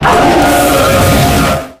Audio / SE / Cries / KYUREM_2.ogg